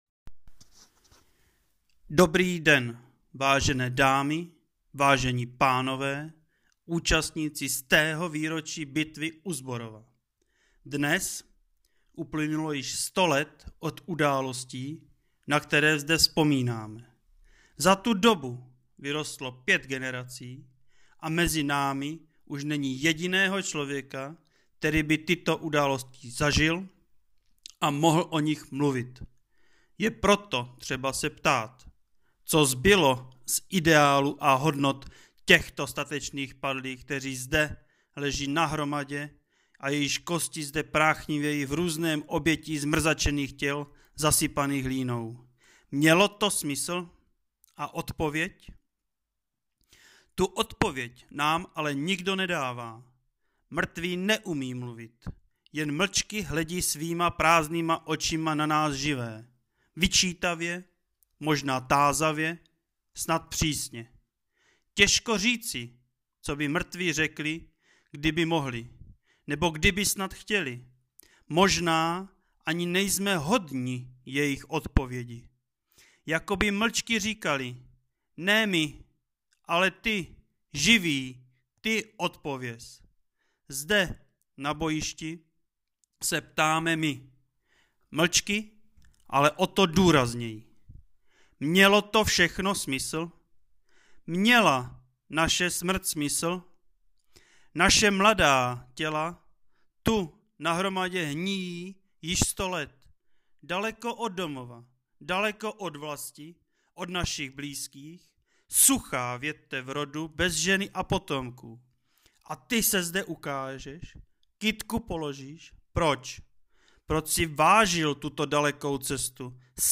Projev k 100. výročí Československé republiky (5 jazyků) + zvukový záznam: